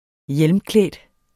Udtale [ -ˌklεˀd ]